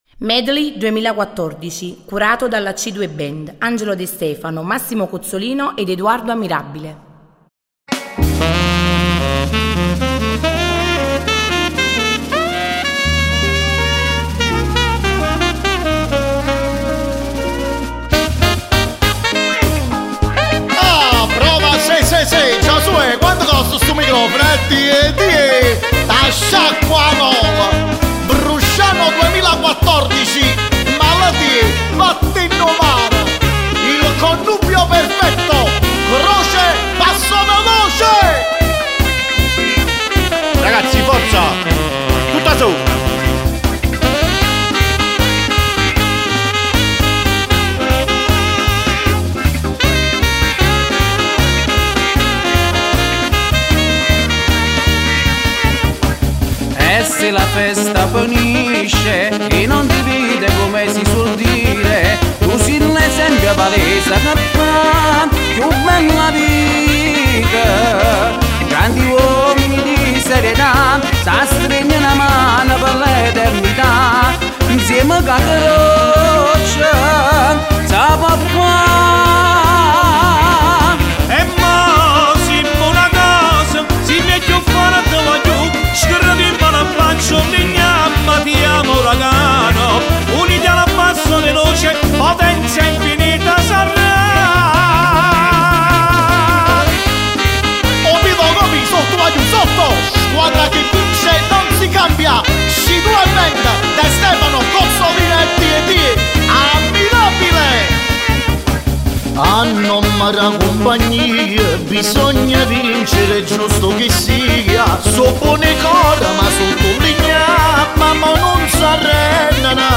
Canzone d'occasione del Comitato Croce 2014 paranza bruscianese Uragano/Passo Veloce
Medley live4.mp3